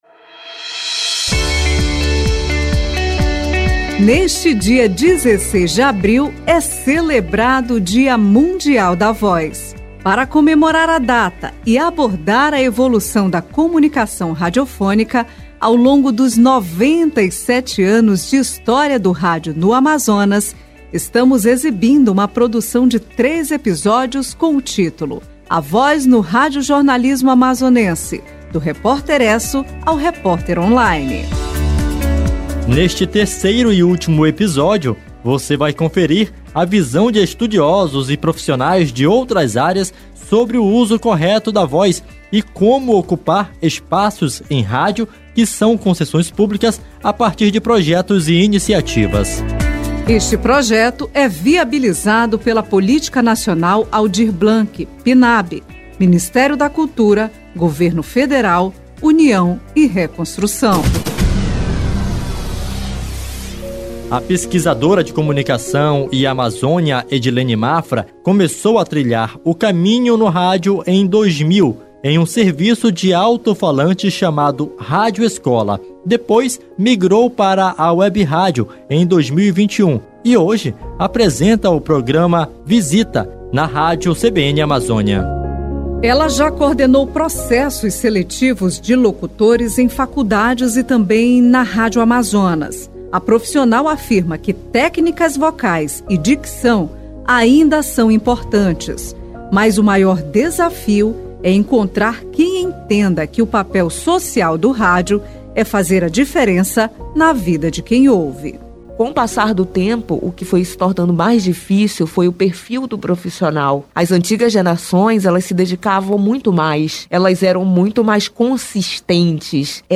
E, neste terceiro e último episódio, estudiosos e profissionais de outras áreas falam sobre o uso correto da voz e como ocupar espaços em rádio, que são concessões públicas, a partir de projetos e iniciativas.